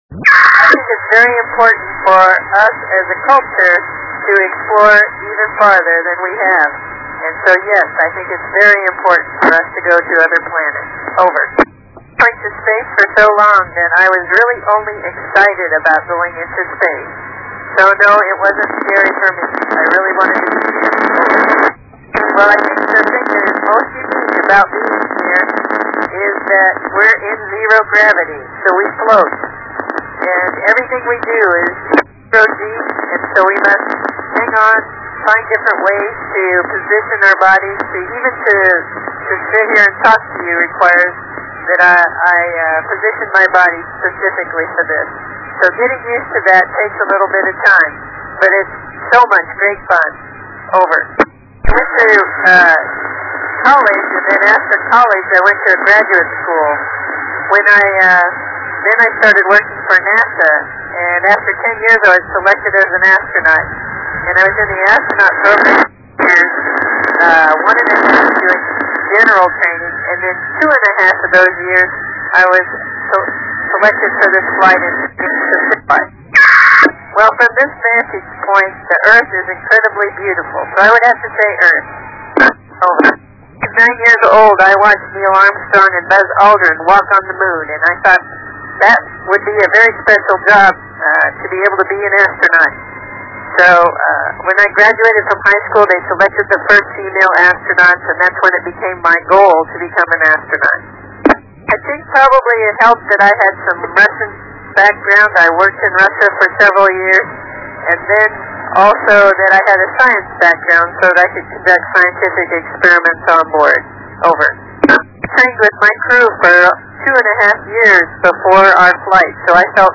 Voice recording
I made of NA1SS and Euro Space Center Belgium